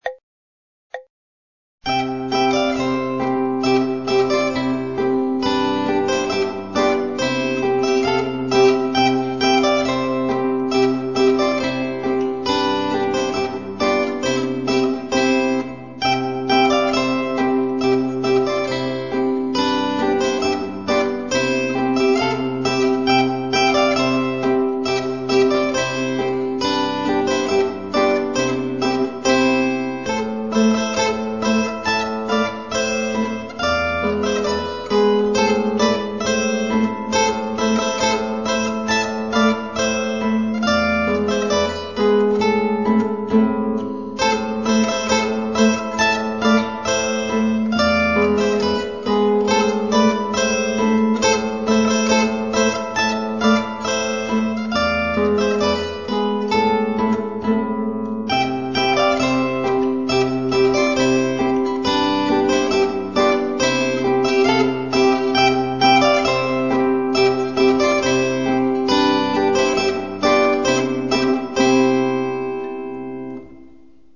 Zehweh Bairischer mit Moll